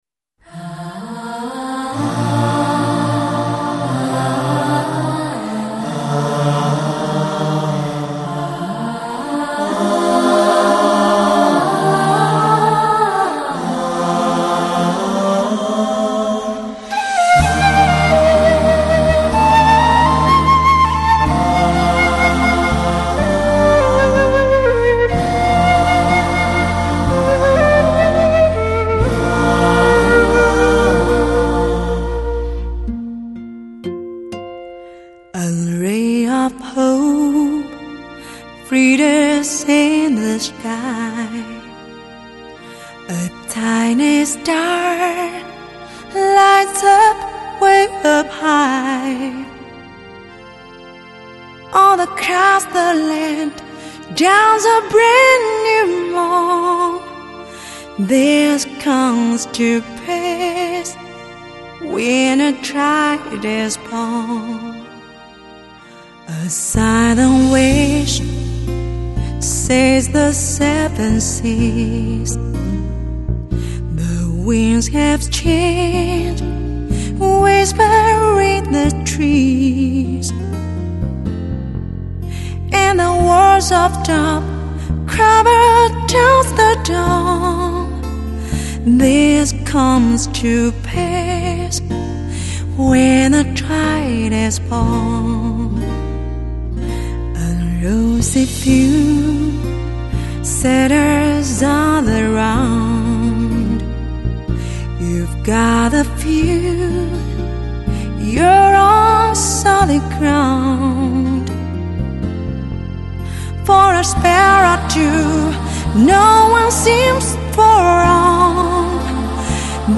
母带后期德国精制
极具现场感的立体声定位
音色通透靓绝，细节表露无遗，发烧级首选